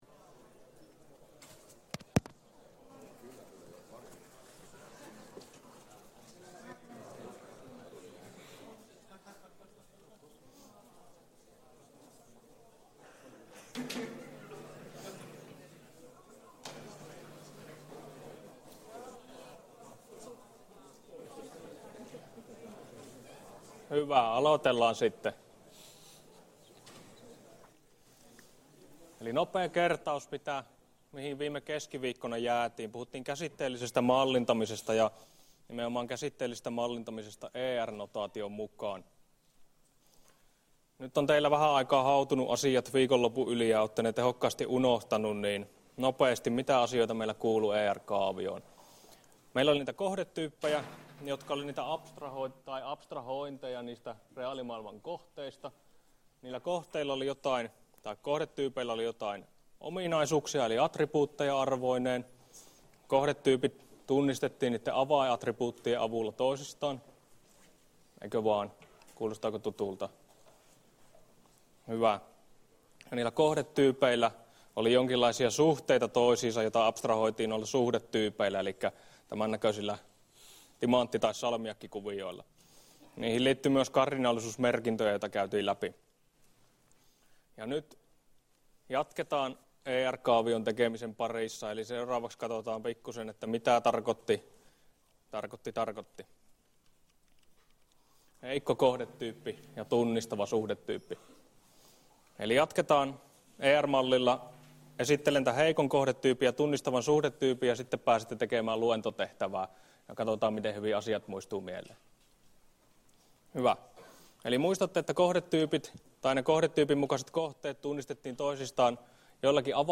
Luento 3 — Moniviestin